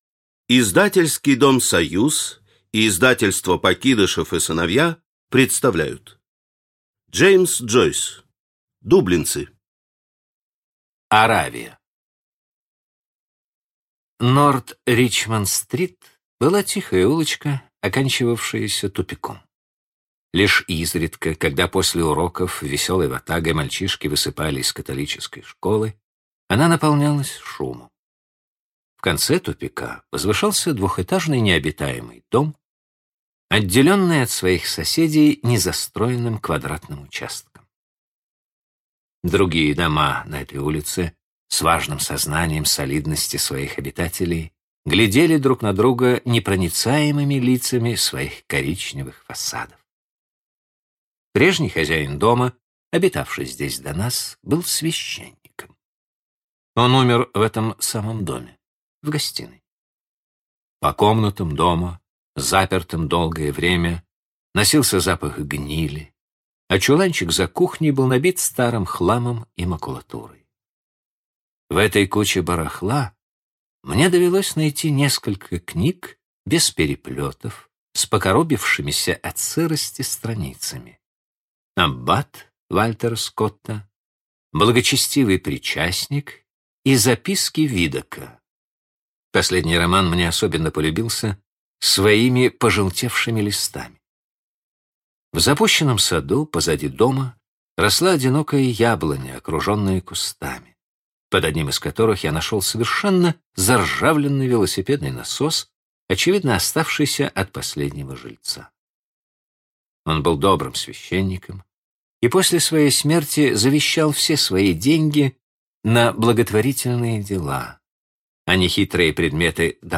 Аудиокнига Дублинцы | Библиотека аудиокниг